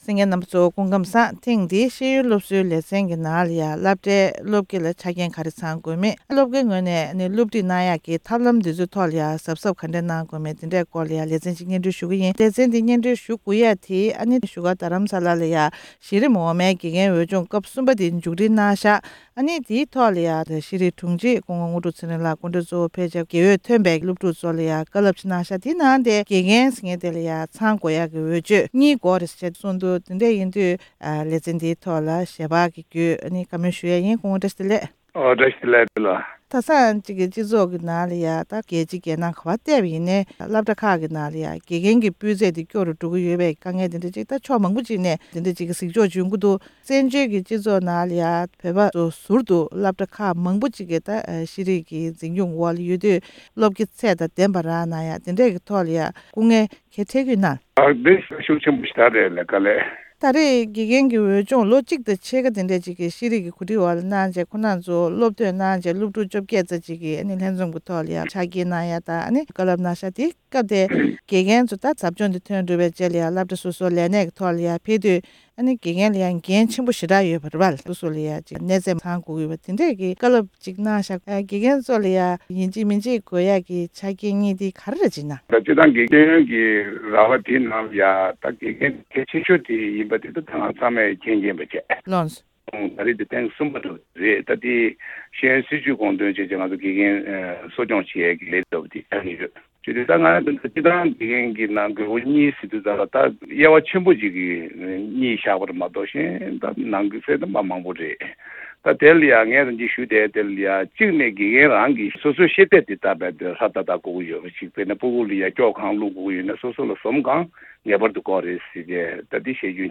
ཤེས་རིག་དྲུང་ཆེ་དངོས་གྲུབ་ཚེ་རིང་ལགས་སུ་བཀའ་འདྲི་ཞུས་པར་གསན་རོགས་ཞུ༎